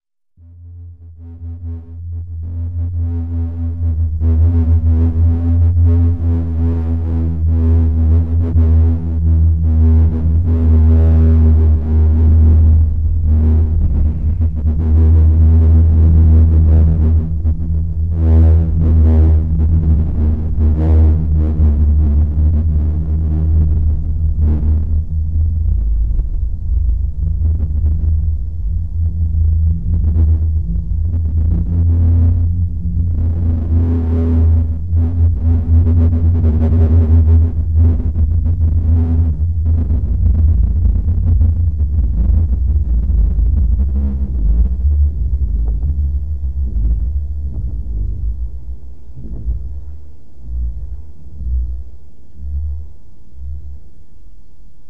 Les dunes chantantes au crépuscule
cliquant ici; elle n'a qu'un lointain rapport avec le chant des sirènes!
Dunes.mp3